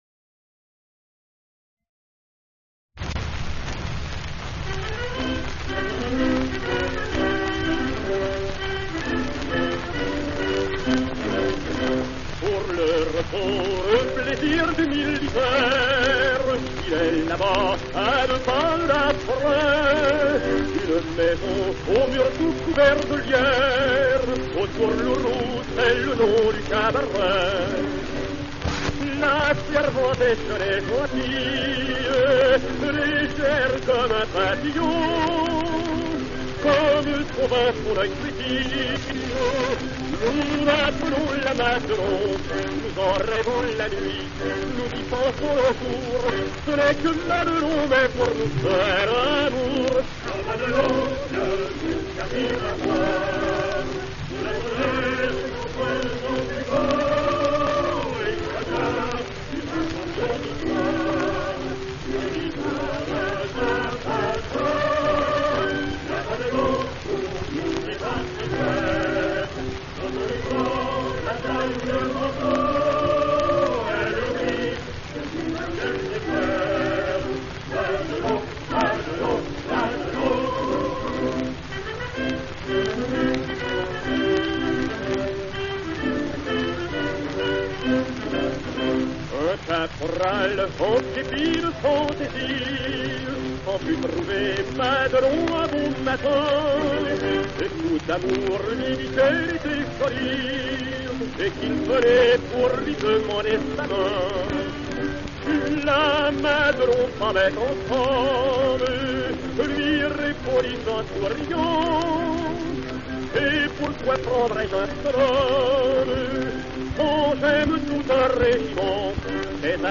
Chanson-marche de la Première Guerre mondiale, existant en plusieurs langues.
Chanson